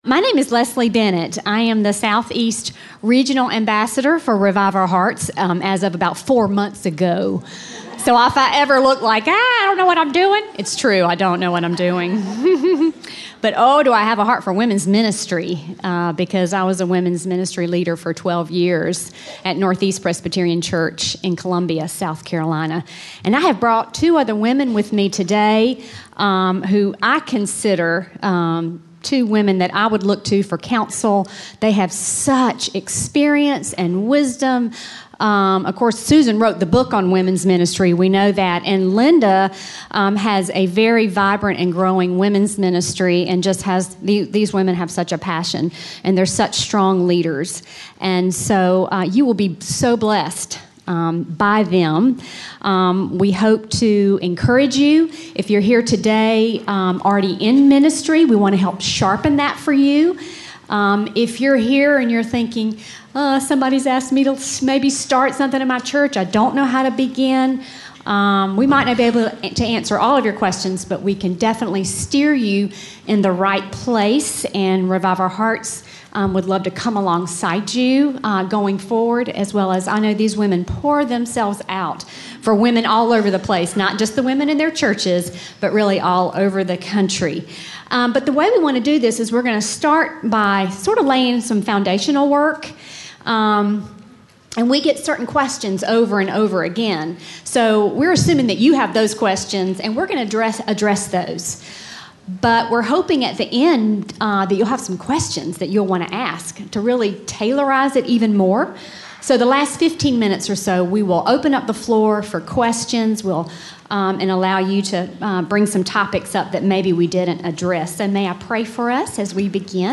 89715-LeadingWomenInTheLocalChurch-Panel.mp3